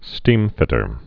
(stēmfĭtər)